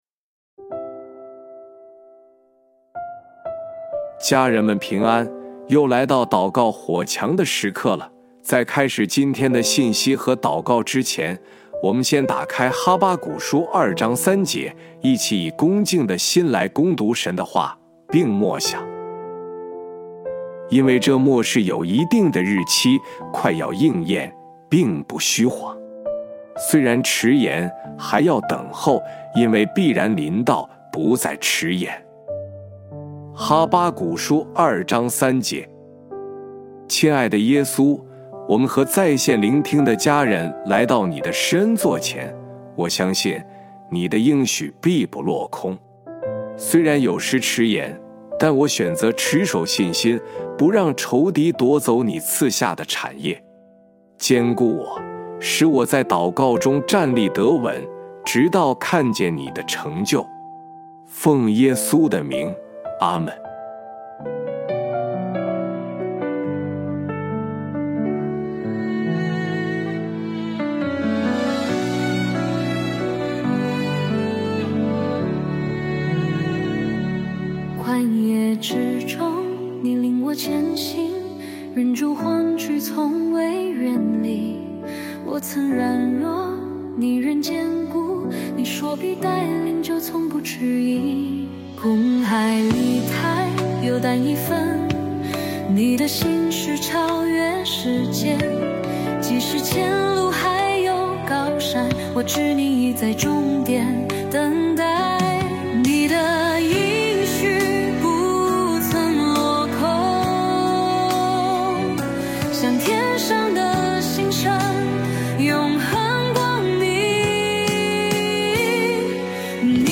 本篇是由微牧之歌撰稿祷告及朗读